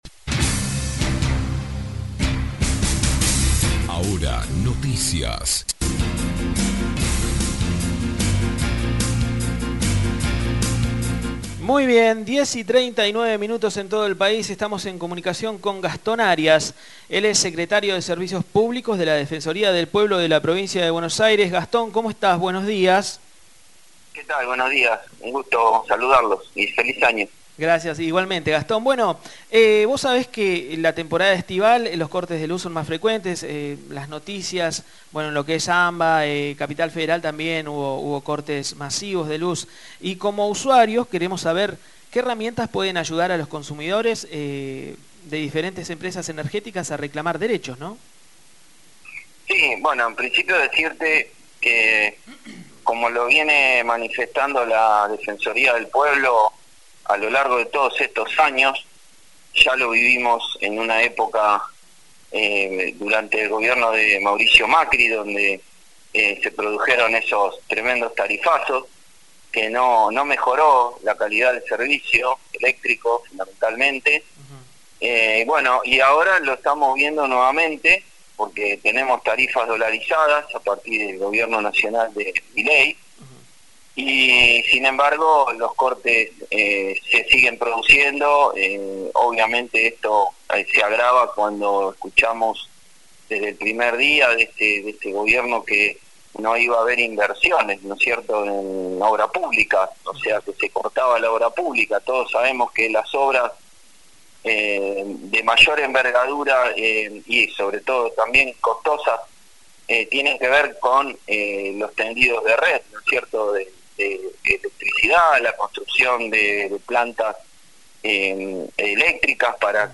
En una entrevista con Gastón Arias, secretario de servicios públicos de la Defensoría del Pueblo de la Provincia de Buenos Aires, le preguntamos cómo los usuarios pueden reclamar en caso de mal manejo de servicios públicos por parte de la Empresas Prestadoras, especialmente durante la temporada estival.